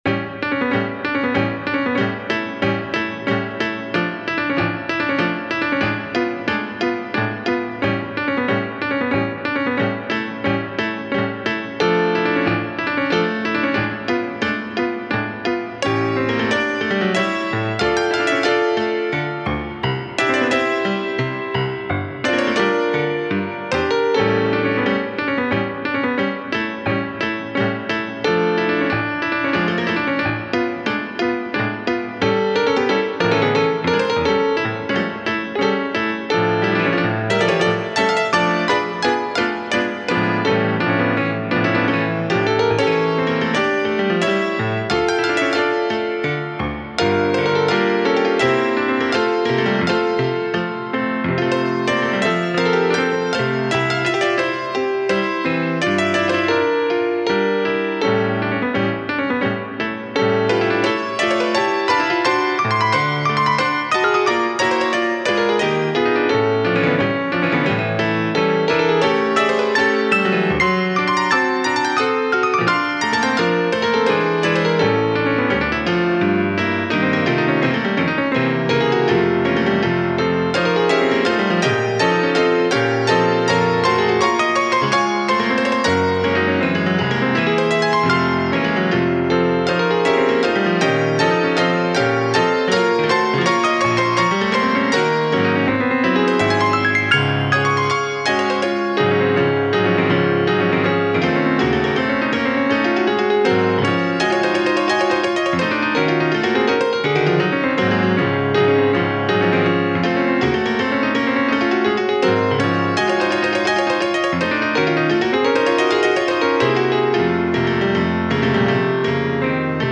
suite
piano
danza
fandango
romanticismo